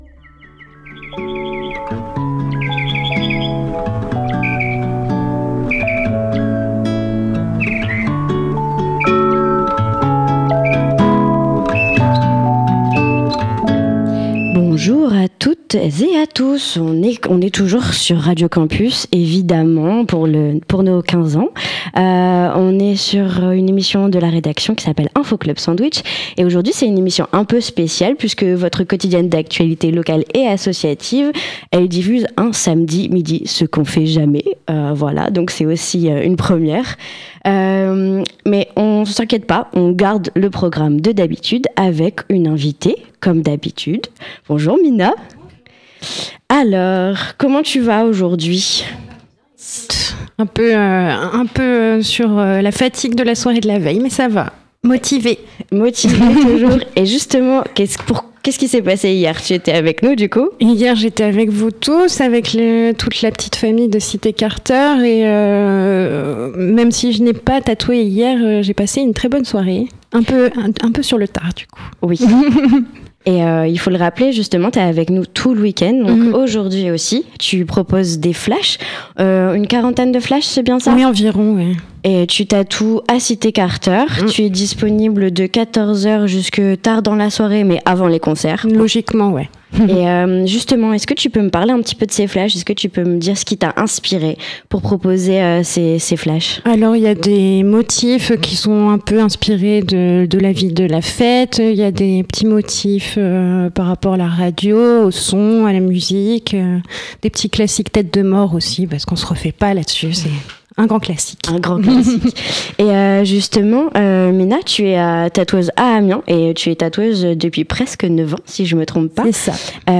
Les 16 et 17 mai 2025, Radio Campus Amiens a continué la fête des 15 ans d’émissions sur les ondes avec le soutien inestimable de toute l’équipe de Cité Carter qui nous a accueilli dans leurs locaux et a assuré la technique en public